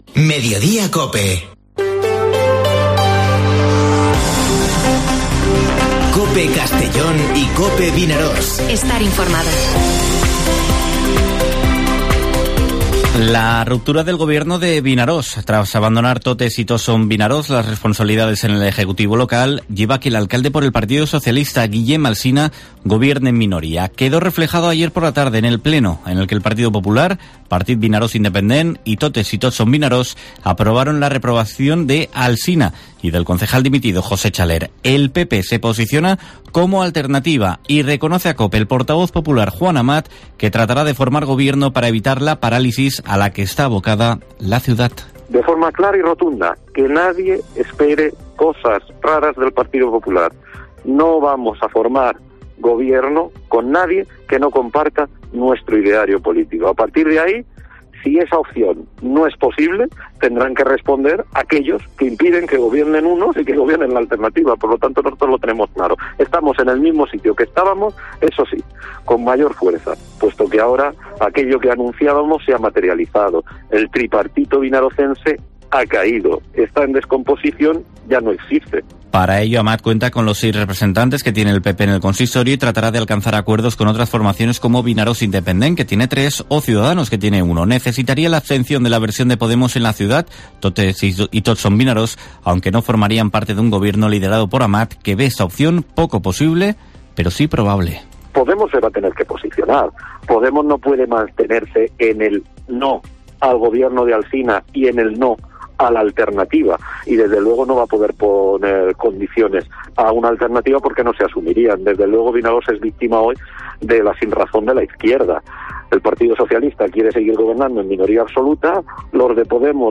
Informativo Mediodía COPE en la provincia de Castellón (20/01/2022)